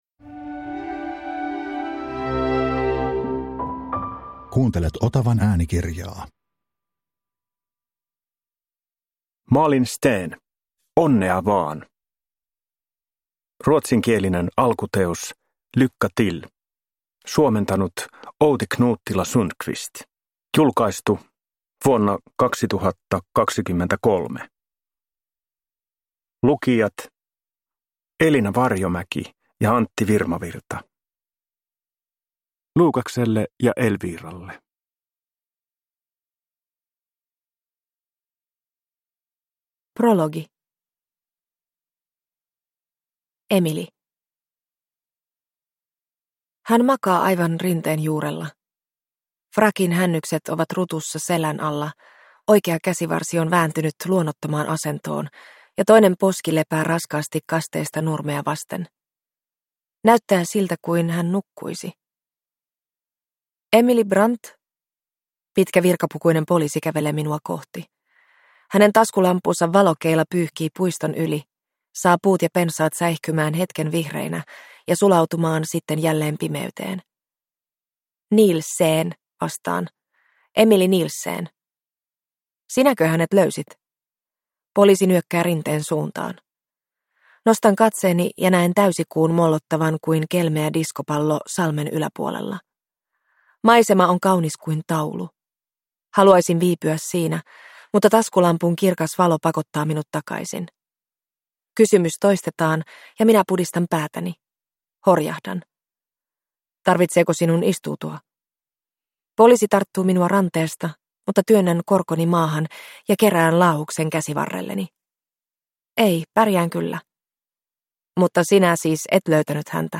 Onnea vaan – Ljudbok – Laddas ner